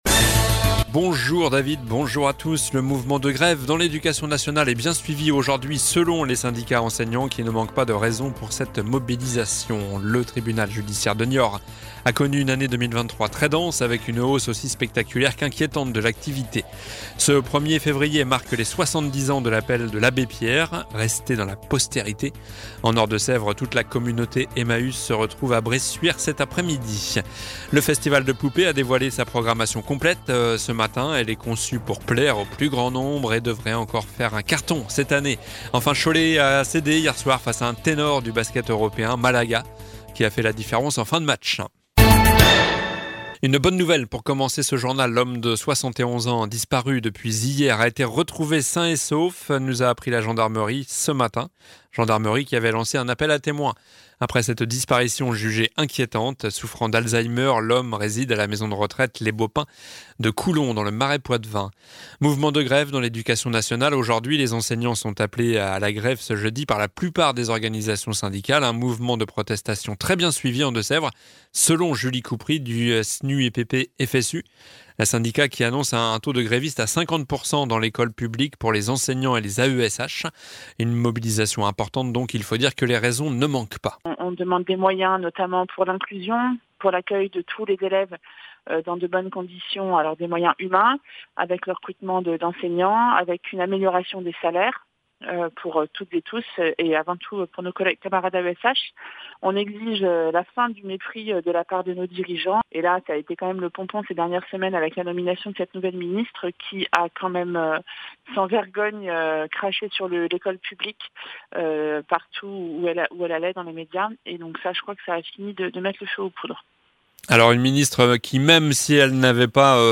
Journal du jeudi 1er février (midi)